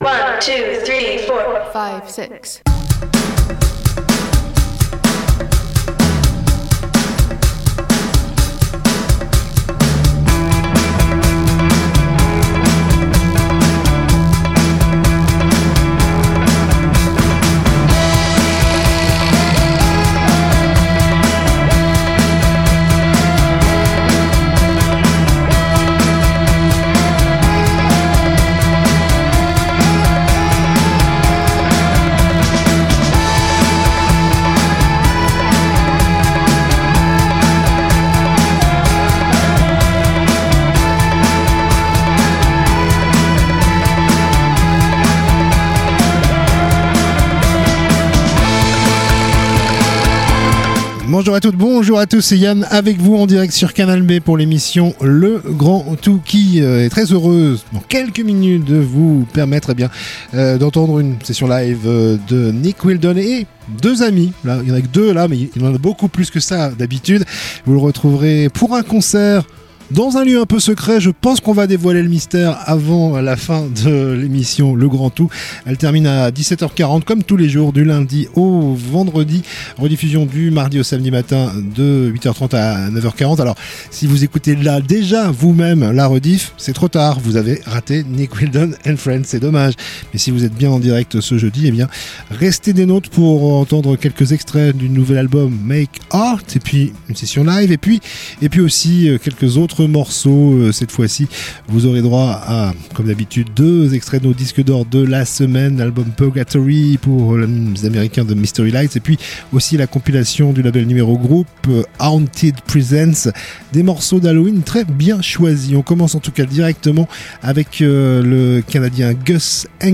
itv musique + session live